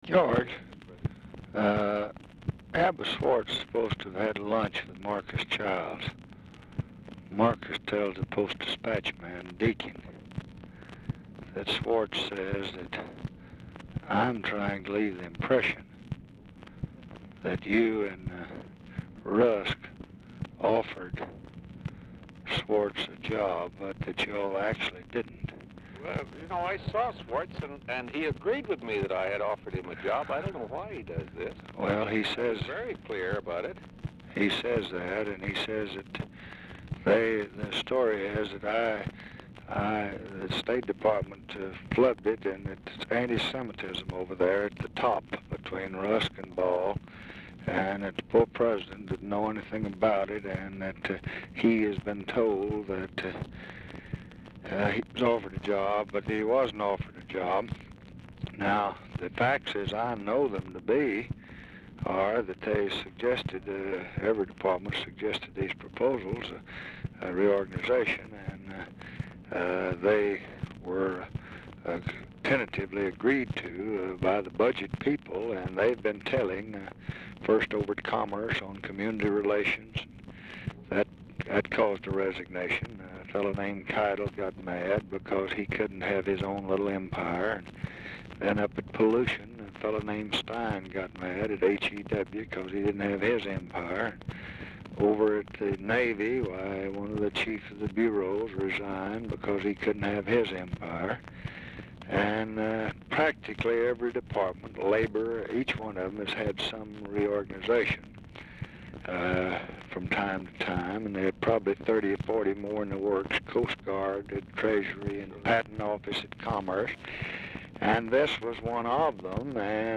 Telephone conversation # 9876, sound recording, LBJ and GEORGE BALL, 3/10/1966, 3:09PM? | Discover LBJ
Format Dictation belt
Location Of Speaker 1 Mansion, White House, Washington, DC